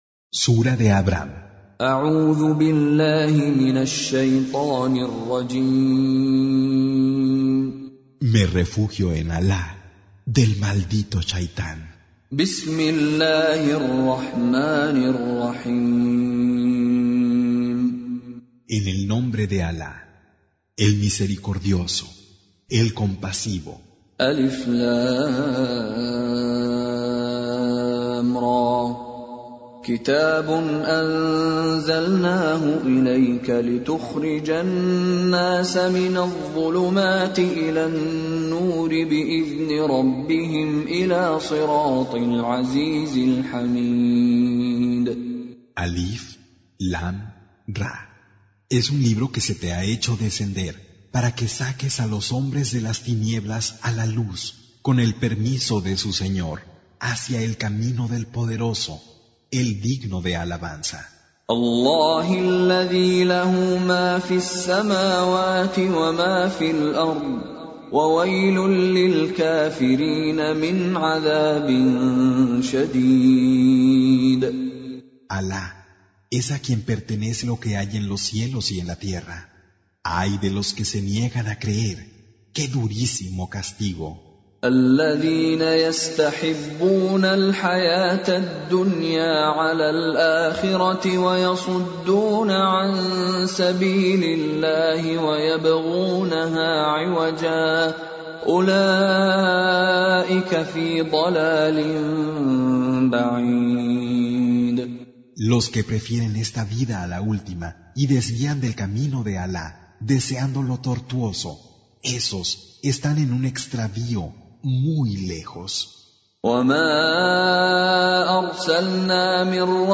Con Reciter Mishary Alafasi
Surah Repeating تكرار السورة Download Surah حمّل السورة Reciting Mutarjamah Translation Audio for 14.